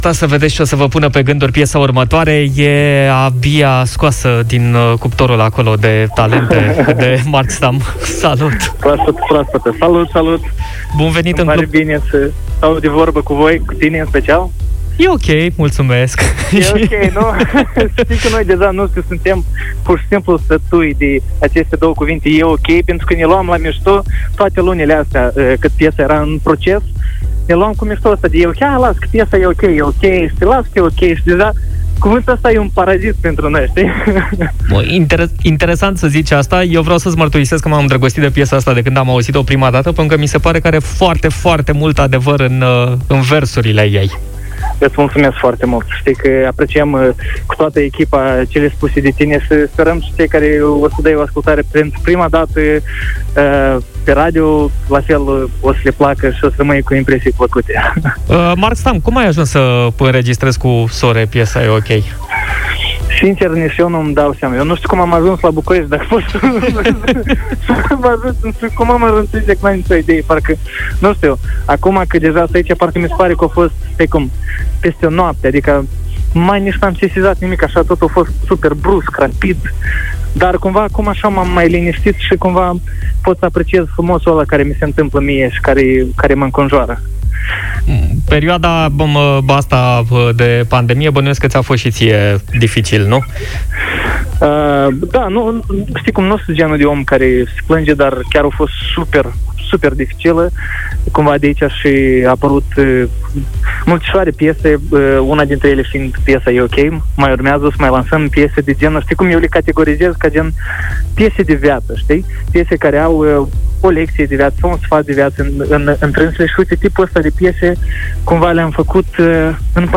Invitat în Clubul de Seară, la Europa FM, Mark Stam a lansat piesa ”E Ok”, înregistrată alături de Sore.